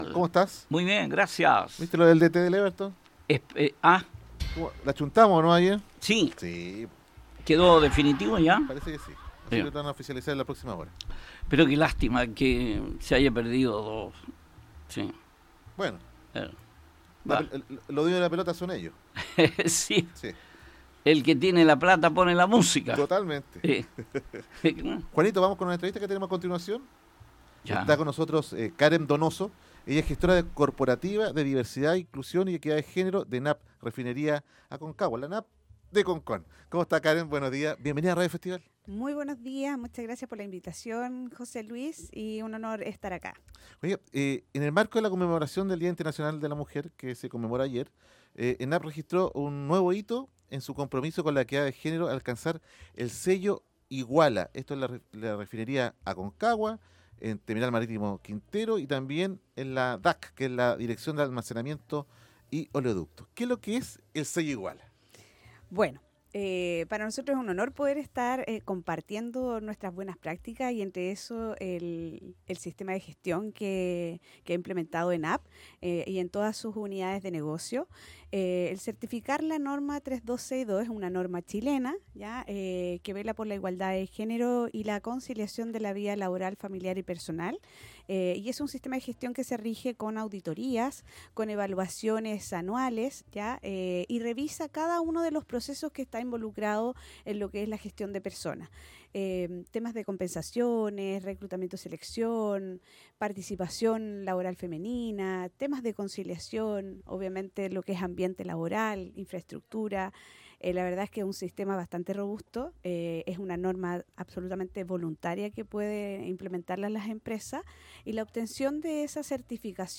estuvo en los estudios de Radio Festival para entregar detalles de los avances en la empresa